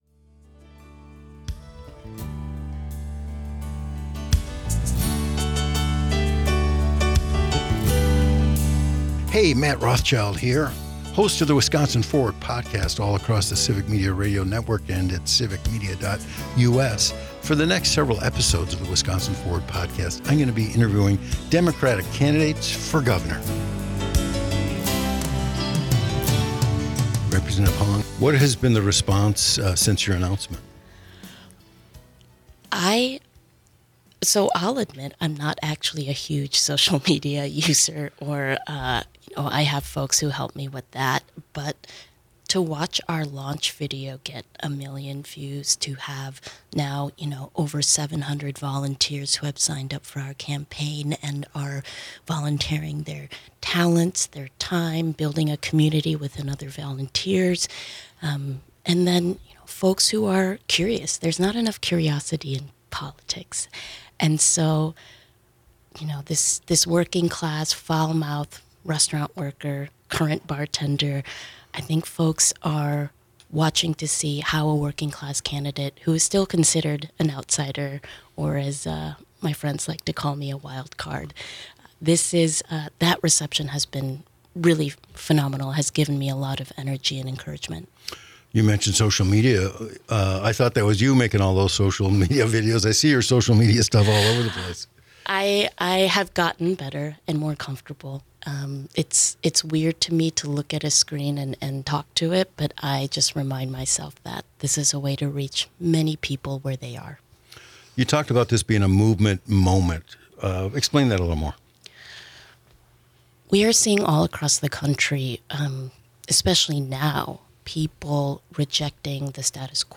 An Interview with Francesca Hong - Civic Media